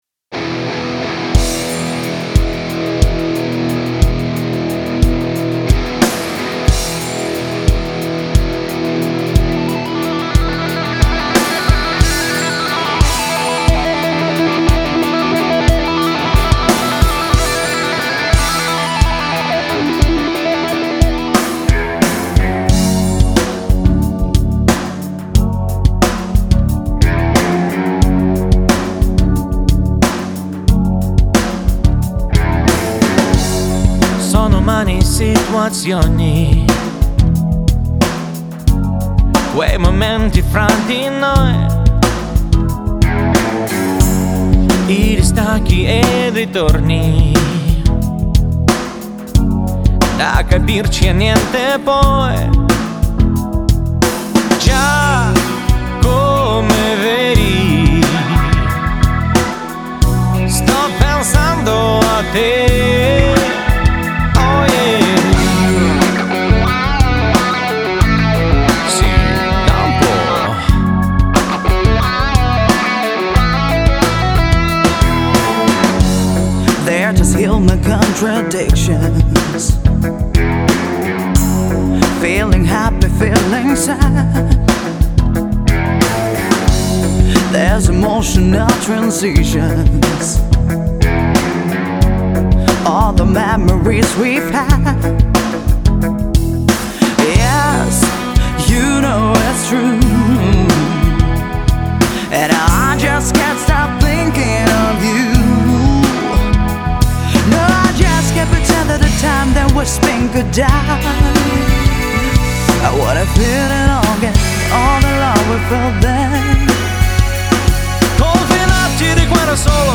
Но после пары переключений туда сюда никакой разницы уловить не могу.